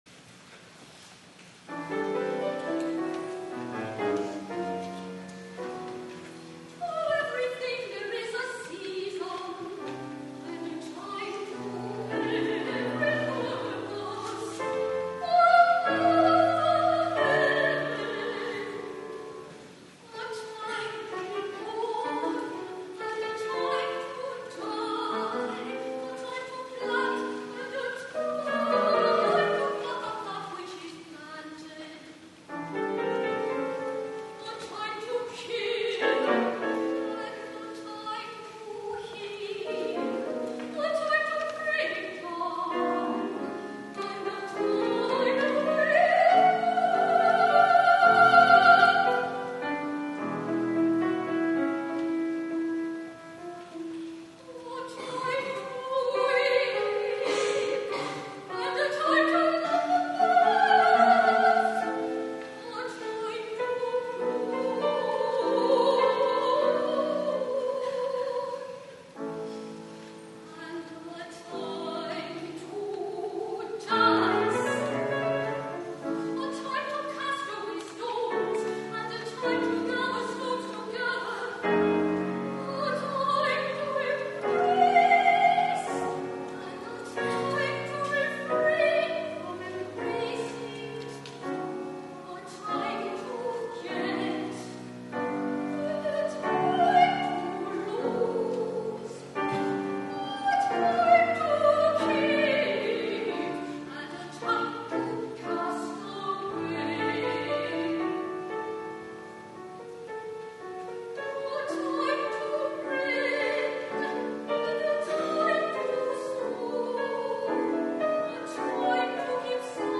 2008 media | Morningside Presbyterian Church
soprano
piano